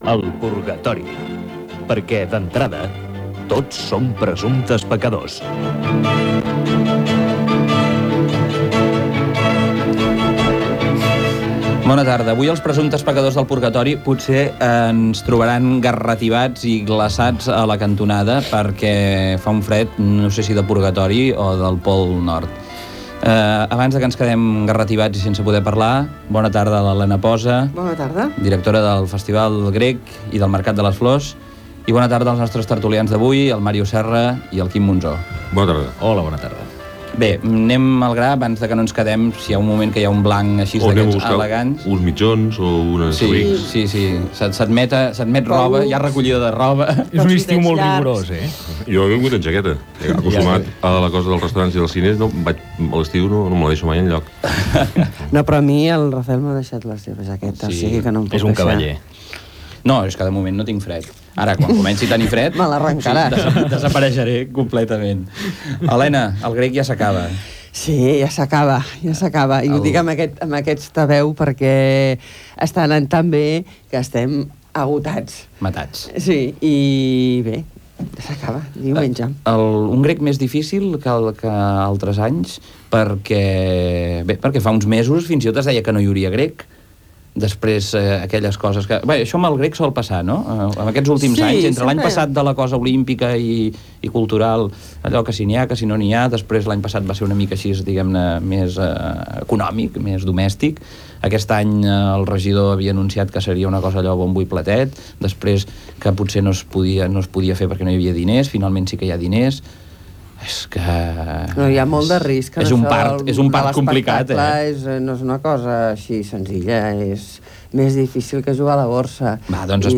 Careta del programa, presentació, inici de la terúlia